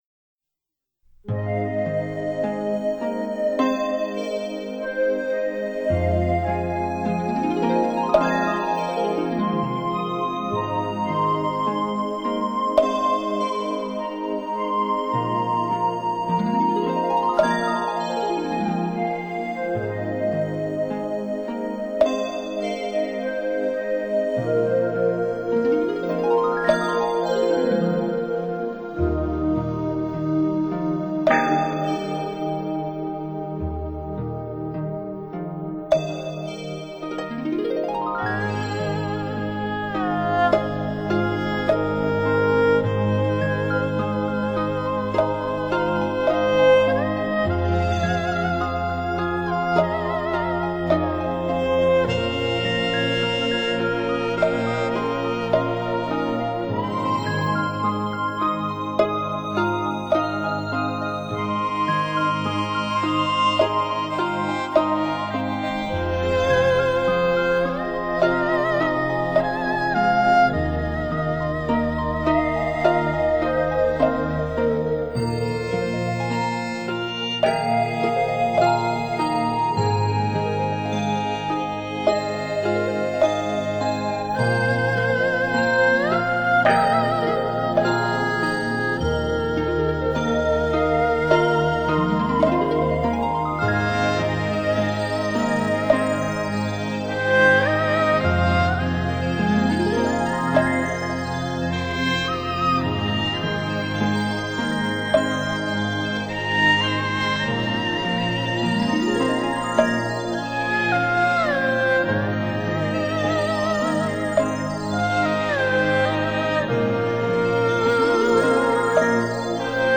宗教音乐